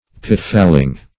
Search Result for " pitfalling" : The Collaborative International Dictionary of English v.0.48: Pitfalling \Pit"fall`ing\, a. Entrapping; insnaring.